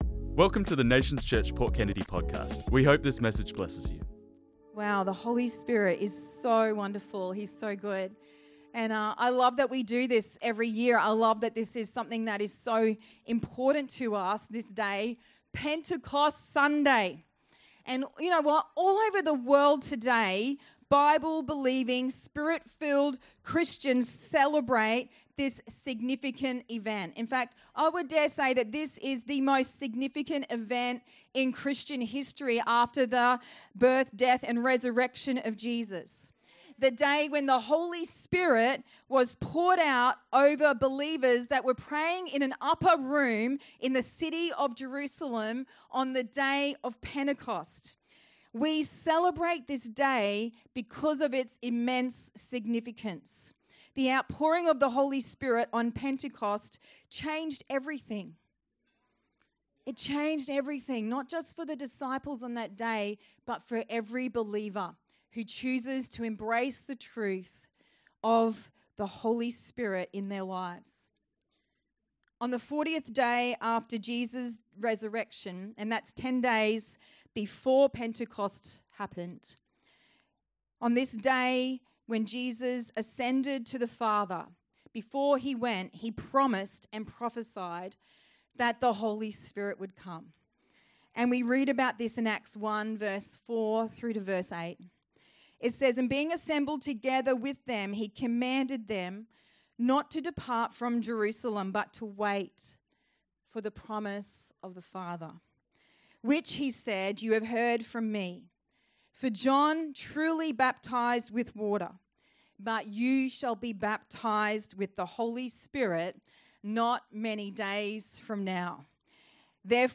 This messaged was preached on Sunday 8th June 2025